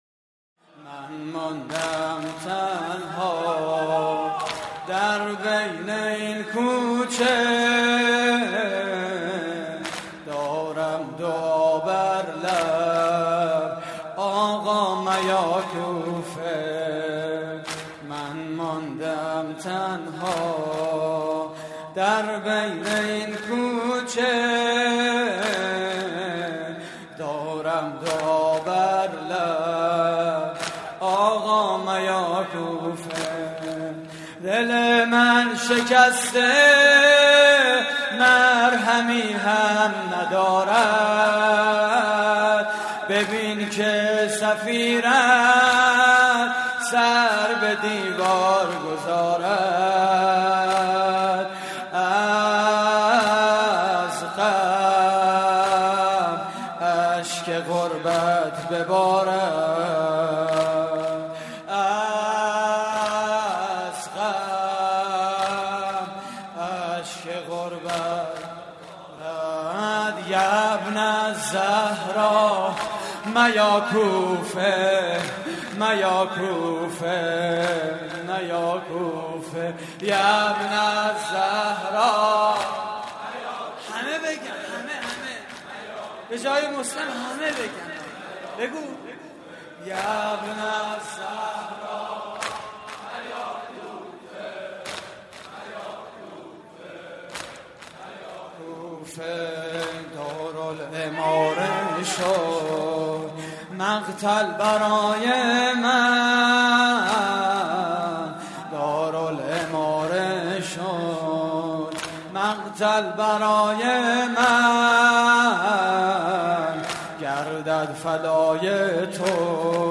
واحد: یابن الزهرا میا کوفه
مراسم عزاداری شب اول ماه محرم